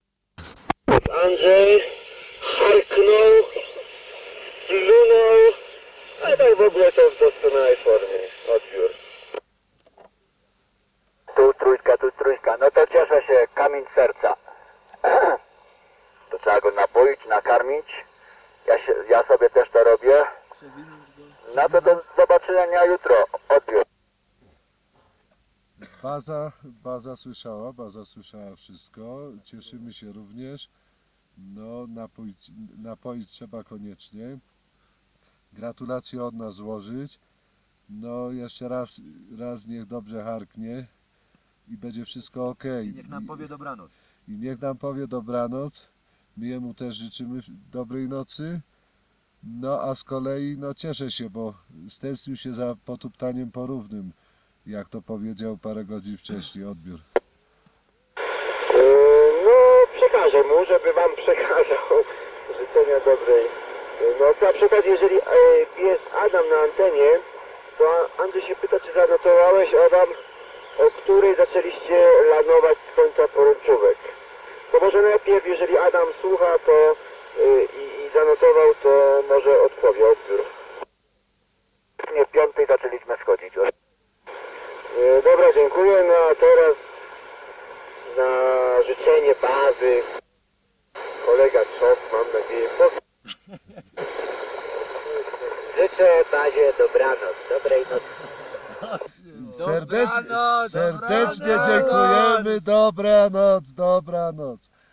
Nagrania łączności radiowej z wypraw wysokogórskich – audialna podróż w przeszłość
Kasety magnetofonowe w pudełkach ochronnych
Fragment 5 – łączność radiowa bazy z obozem (Makalu 1982)